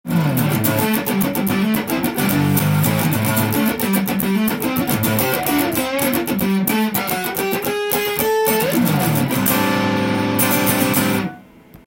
センターピックアップでカッティングしてみました。
カッティング系も良い感じでジャキジャキ鳴ってくれるので